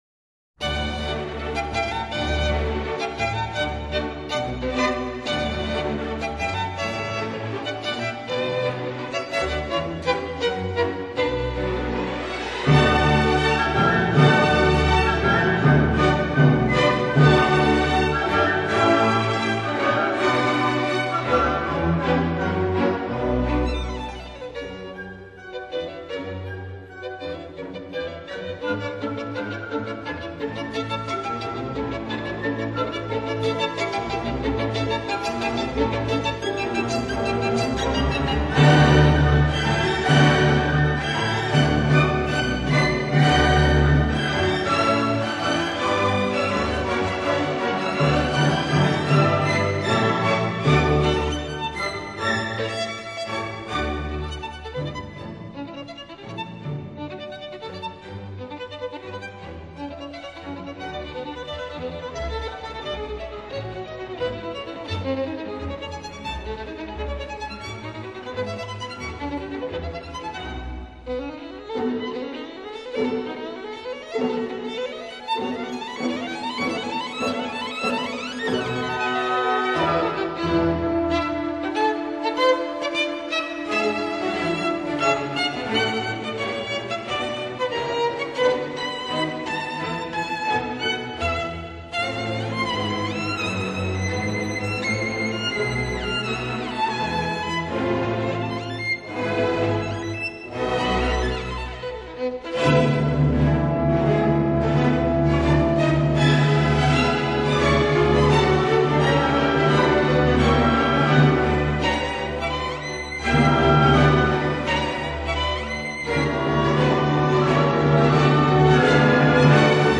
旋律轻快活泼, 抒情优美 又不乏震撼人心的气势!